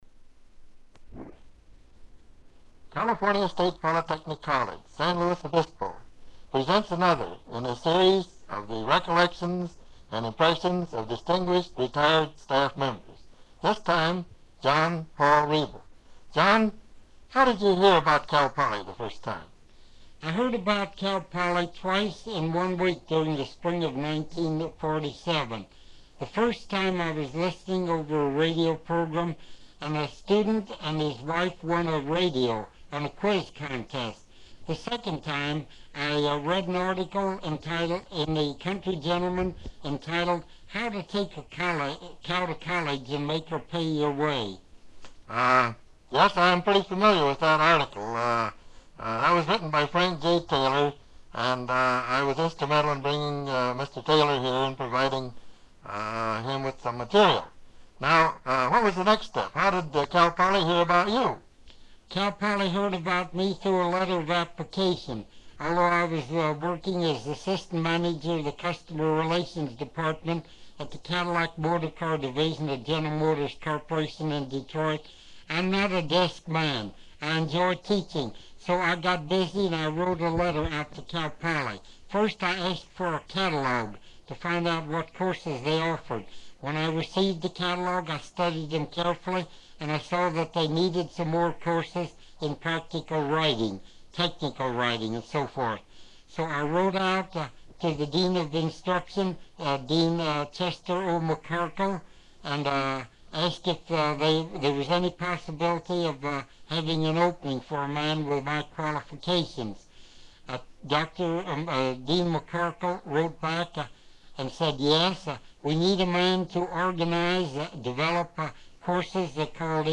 Interview wrap-up
Open reel audiotape